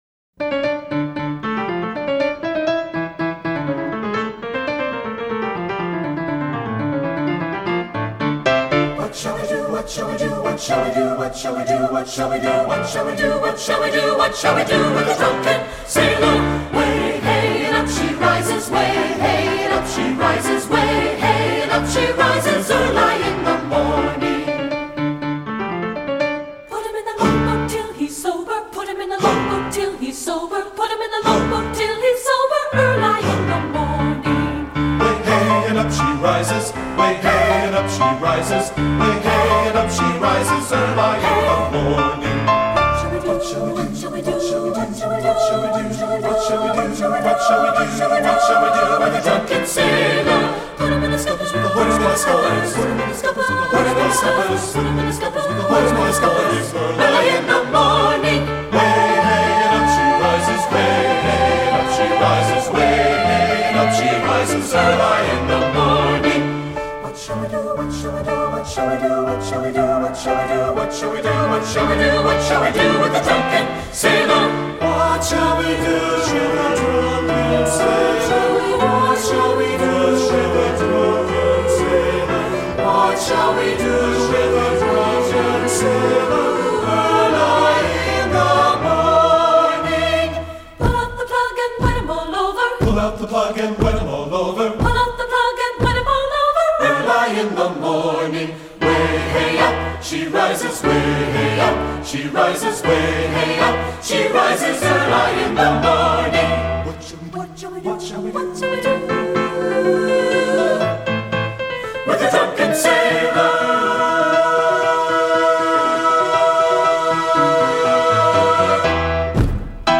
Composer: Sea Chantey
Voicing: SATB